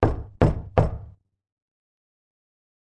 敲敲打打" 敲打3倍的声音
描述：敲击木桌的变化